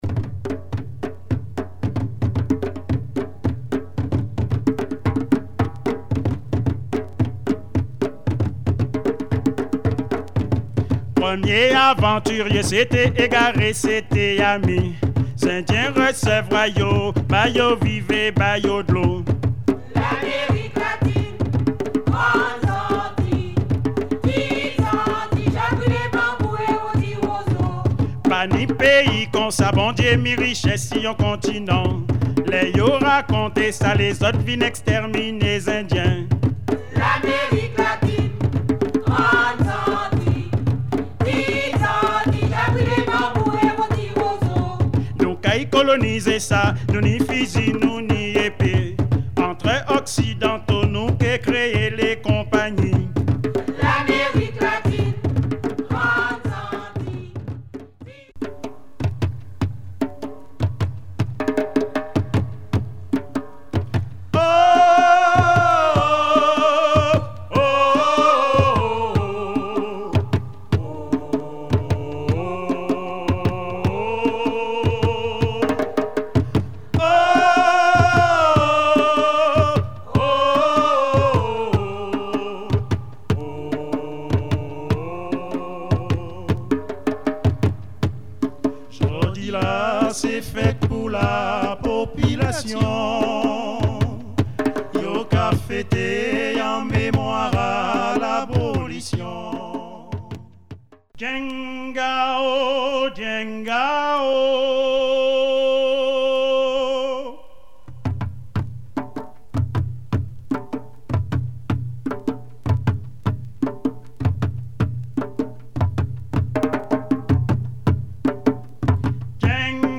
Deep gwo ka percussion, and needless to say it's rare.